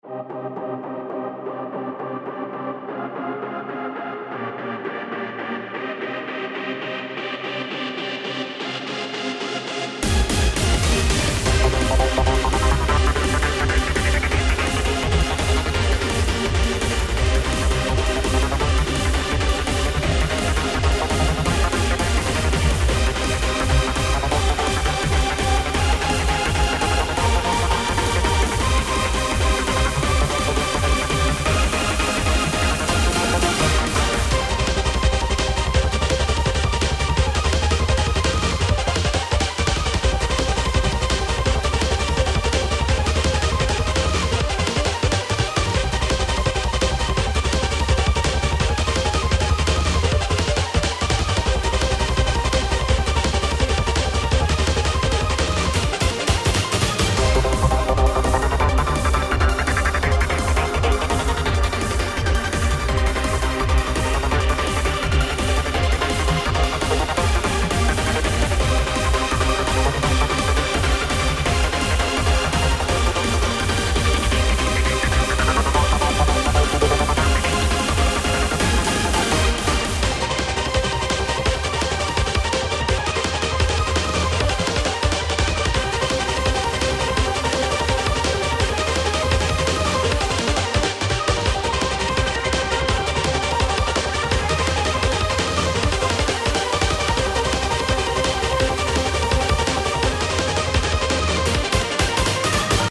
💬宇宙のように壮大で底知れない謎を秘めたキャラあるいは概念をイメージした戦闘BGMです。
曲の雰囲気的に弾幕STGやSF系の世界観に合いそうな気がしています。